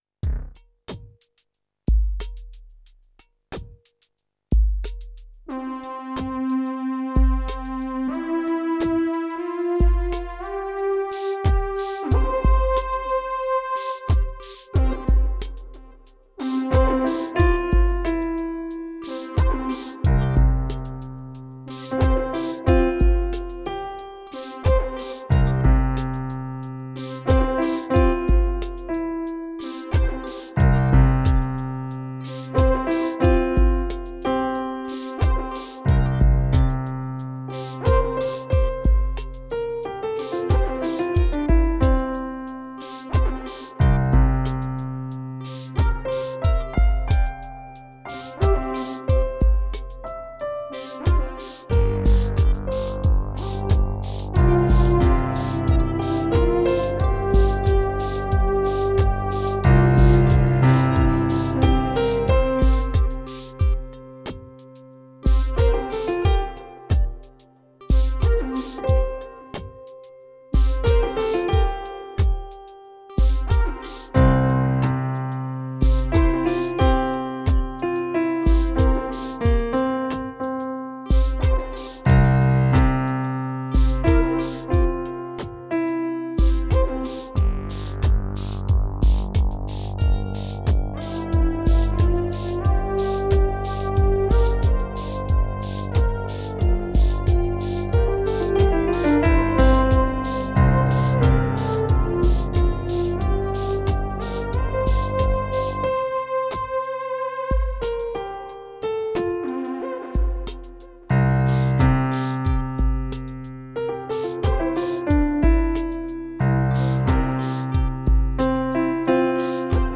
Tempo: 60 bpm / Datum: 06.04.2017